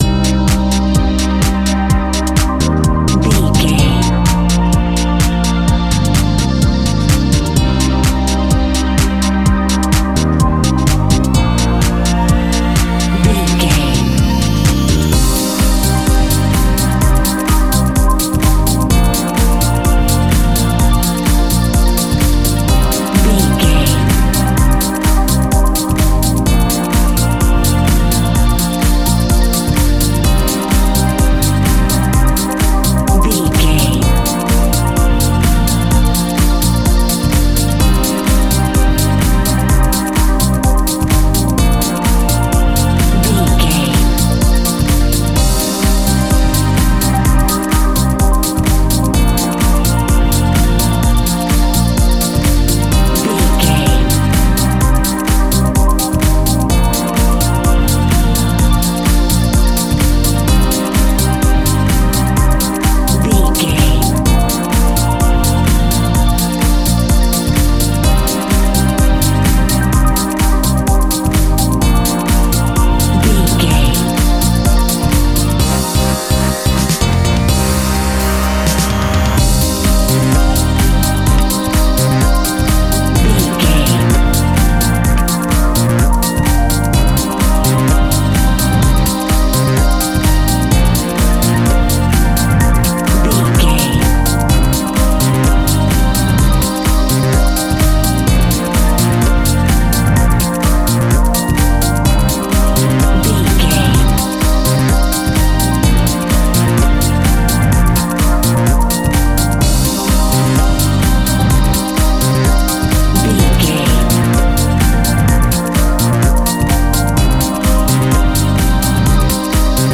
Aeolian/Minor
groovy
uplifting
energetic
funky house
electro funk
upbeat
synth drums
synth bass
synth lead
Synth pads
electric piano
bass guitar
clavinet
horns